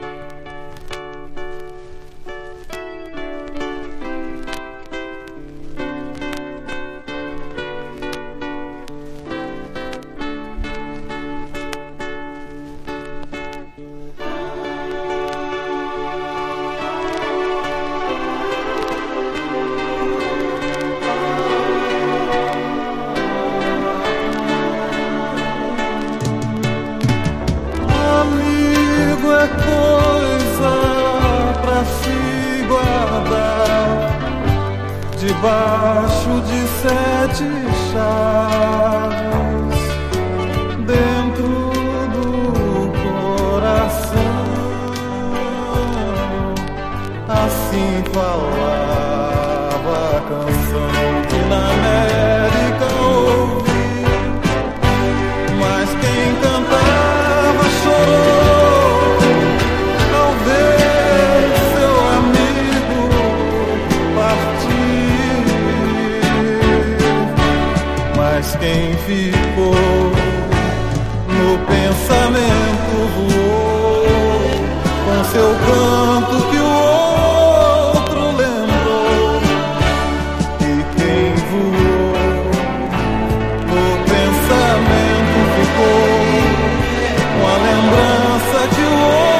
美しいメロディとエモーショナルなヴォーカルがクロスオーヴァーする良質のブラジリアン・ナンバー全13曲収録！
# FREE SOUL# LATIN